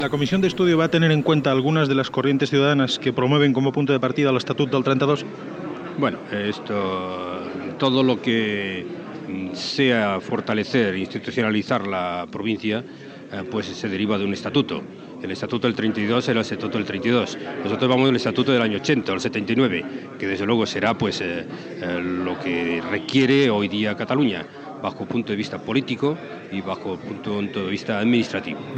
Pregunta al preseident de la Diputació de Barcelona Juan Antonio Samaranch sobre la Comissió anomenada “d’estudi per un règim especial per les províncies catalanes”.
Informatiu